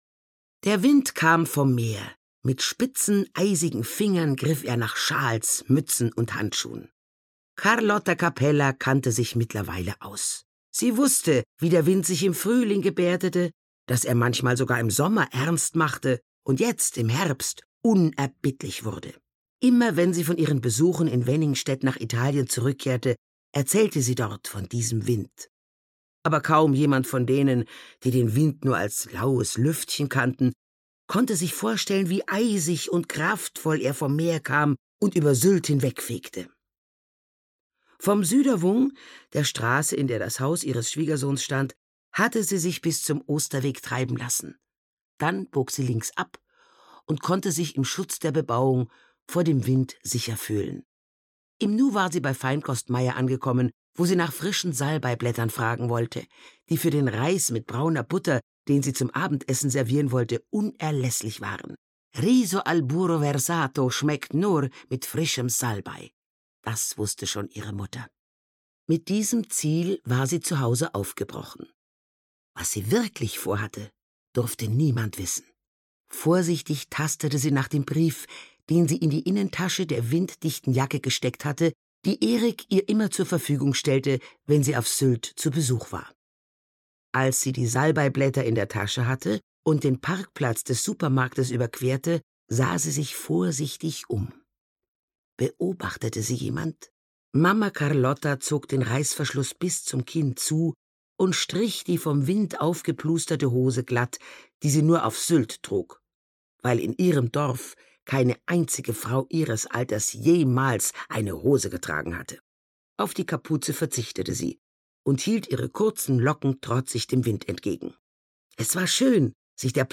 Kurschatten (Mamma Carlotta 7) - Gisa Pauly - Hörbuch